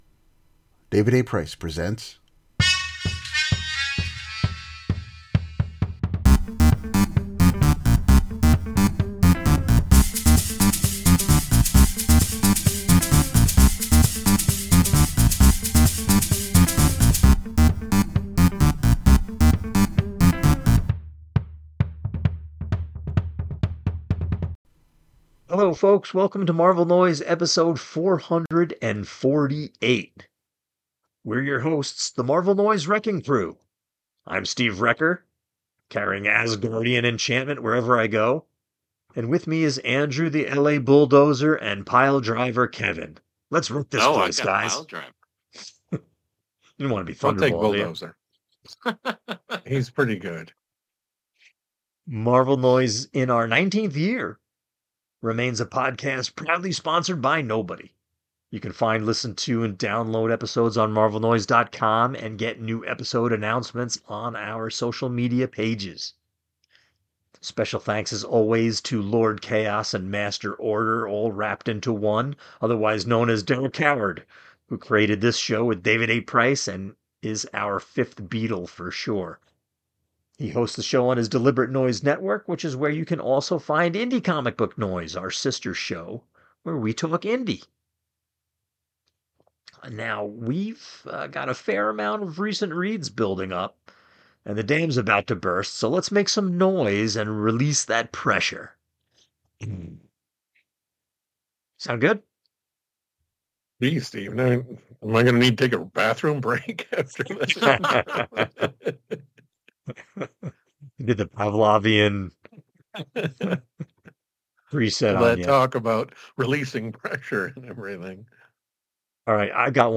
Marvel Noise Episode 448 – February Recent Reads Roundtable